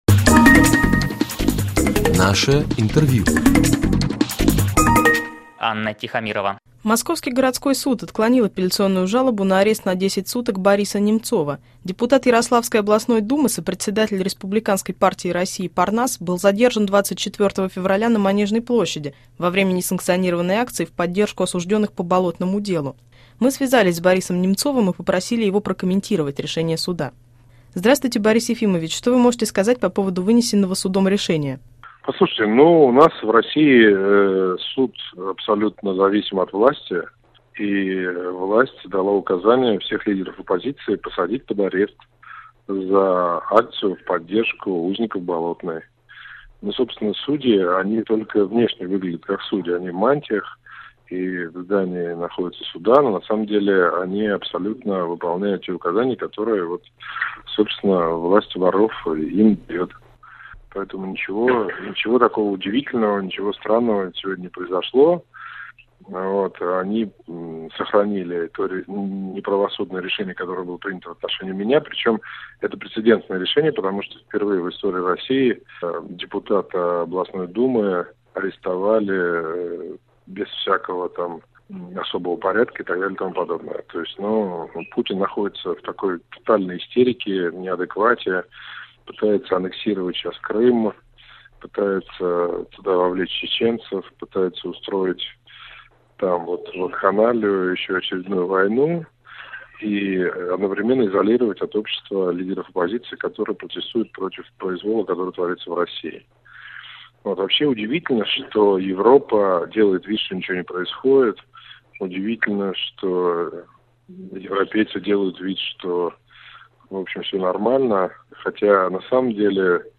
История. Интервью с Борисом Немцовым
Об этом Борис Немцов рассказал в интервью RFI.